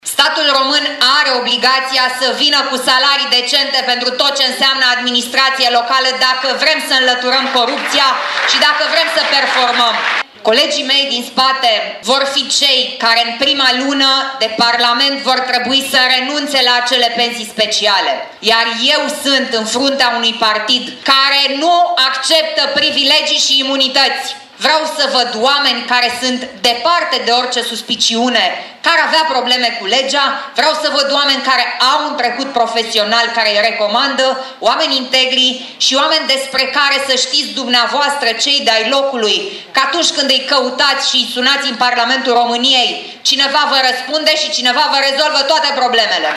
Președintele PNL, Alina Gorghiu, a participat, la Casa Culturii Suceava, la lansarea candidaților PNL pentru Senat și Camera Deputaților.